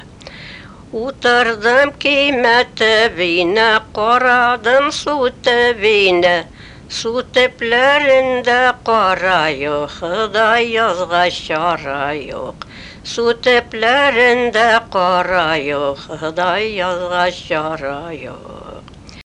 При пропевании слоговой объем поэтических строк может увеличиваться почти вдвое за счет огласовок и добавочных слогов (Аудио 10) или же оставаться неизменным (Аудио 01, нотация приведена в [8]).
Основой большинства чатских напевов являются пятиступенные бесполутоновые лады в объеме сексты–септимы, которые фиксируются в песнях всех жанров.